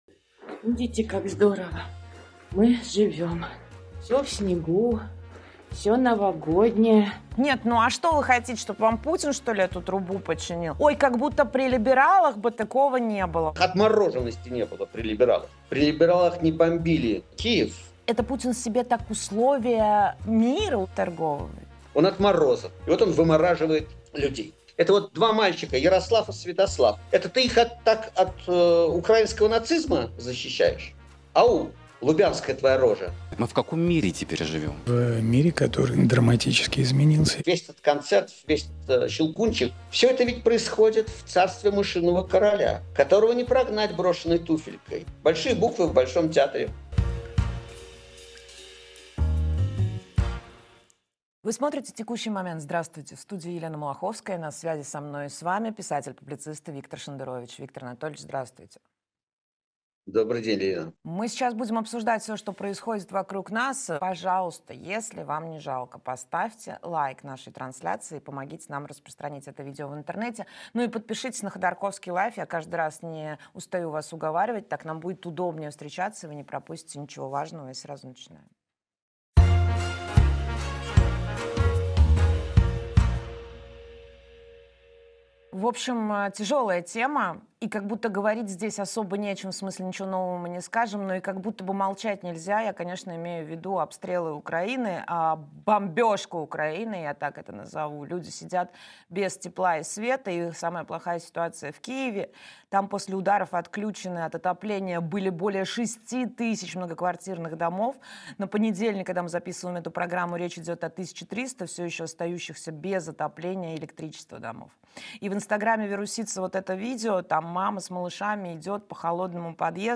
Виктор Шендерович писатель